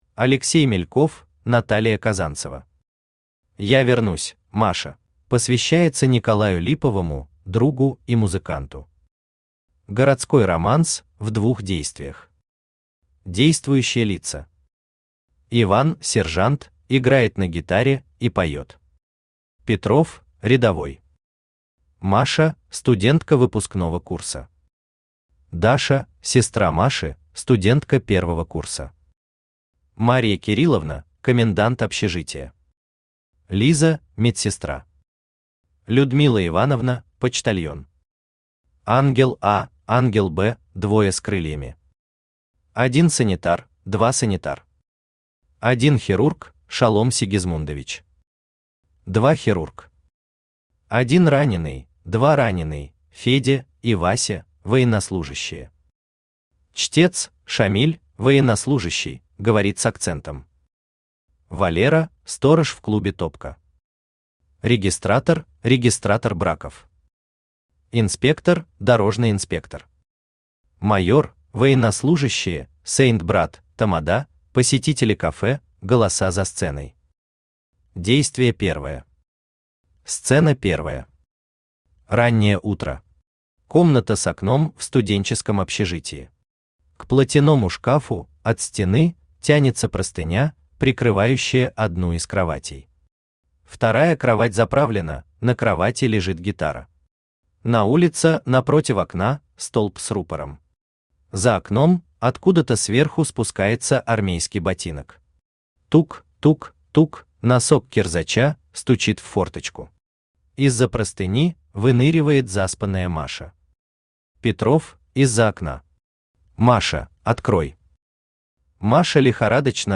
Аудиокнига Я вернусь, Маша!
Автор Алексей Николаевич Мельков Читает аудиокнигу Авточтец ЛитРес.